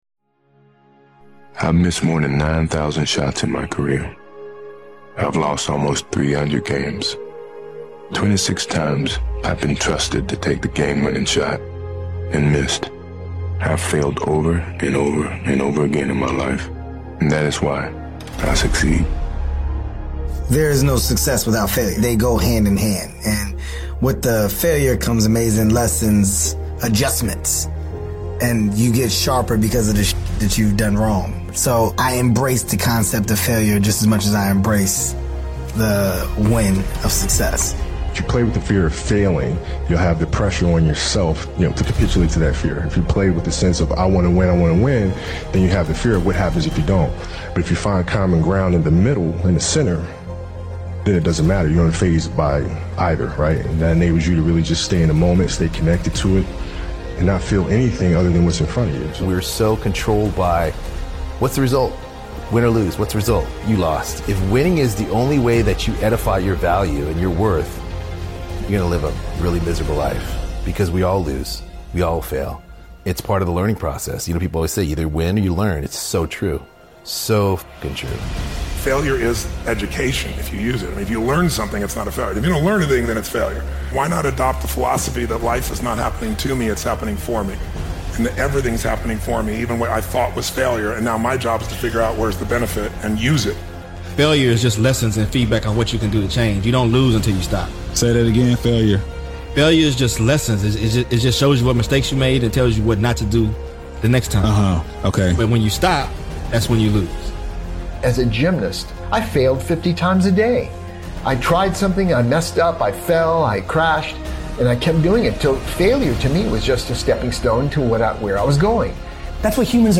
The best in the world know they will fail again and again, but they have learned how to deal with it. Best Motivational Speeches from Motiversity, featuring speeches from Michael Jordan, Kobe Bryant, Lebron James, Tom Brady, Mike Tyson, and more.